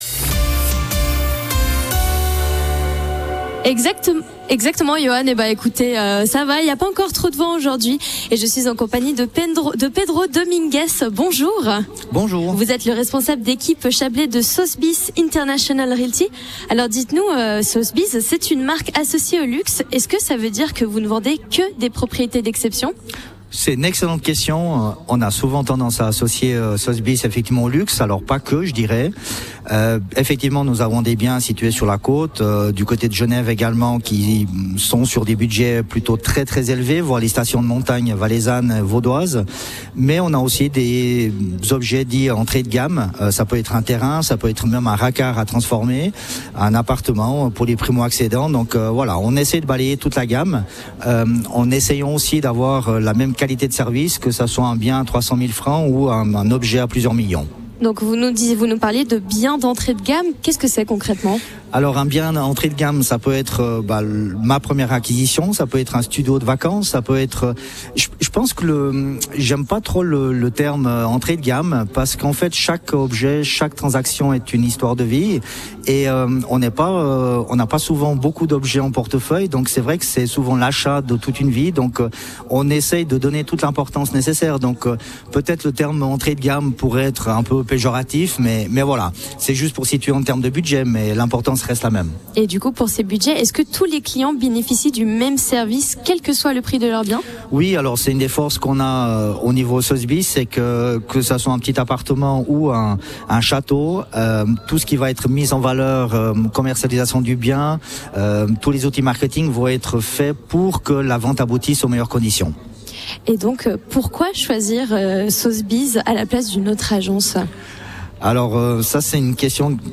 Interview de Radio Chablais